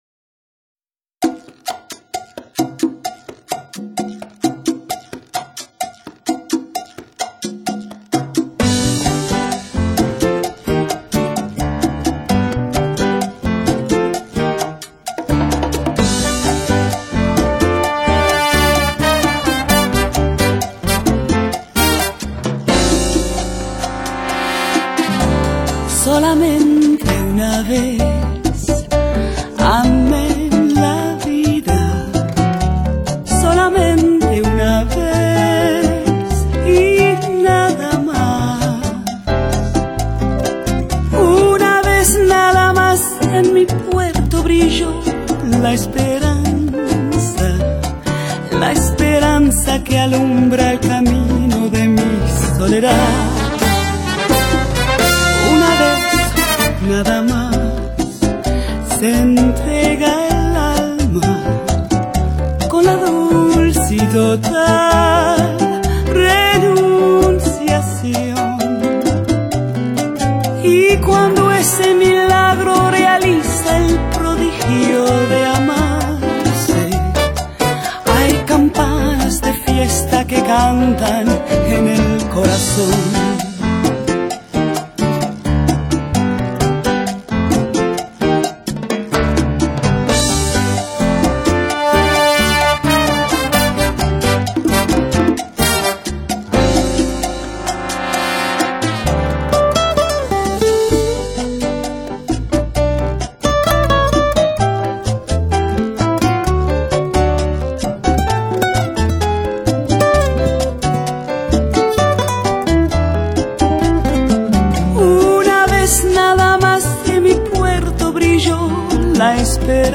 拉丁风情的曼妙JAZZ……
火热而纯正的伦巴、恰恰、莎莎、波列罗节奏